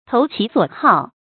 注音：ㄊㄡˊ ㄑㄧˊ ㄙㄨㄛˇ ㄏㄠˋ